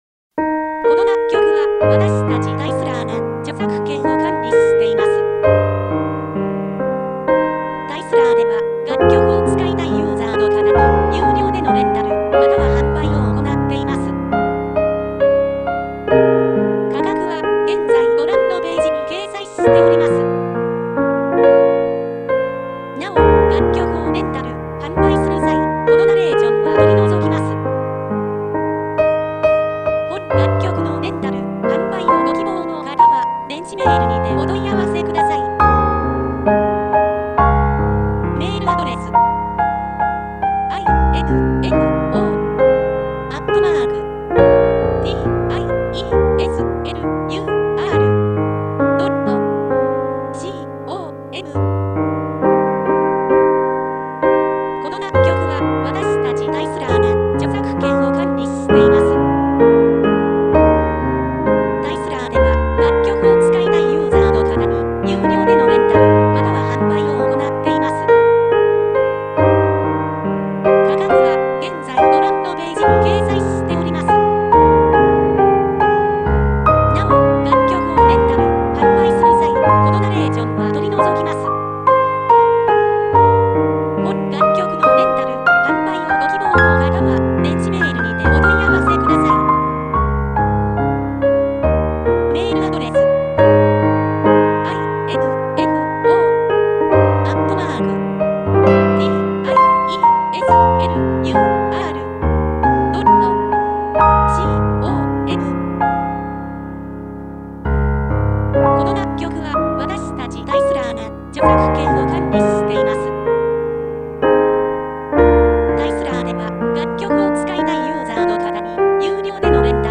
■ピアノ曲のレンタル・販売ページ
●メジャーキー・スローテンポ系